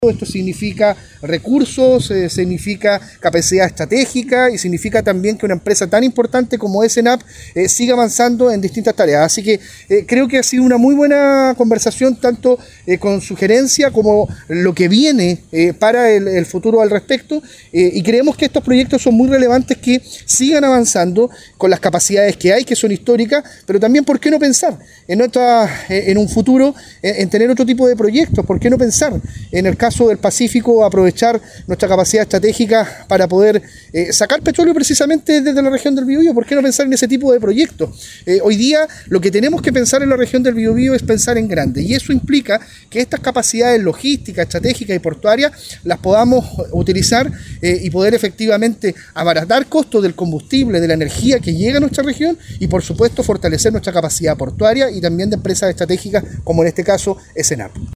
Al término de una visita técnica a la planta, el delegado presidencial regional, Eduardo Pacheco, destacó que a dos años de la recuperación de este oleoducto, “vemos que avanza de muy buena manera, sin ningún contratiempo, aumentando los niveles de crudo que están llegando y generando, por ejemplo, en el caso de Enap, una estabilidad respecto de la recepción de este insumo para las distintas tareas que desarrolla la empresa estatal y una serie de empresas internacionales que requieren de productos refinados”.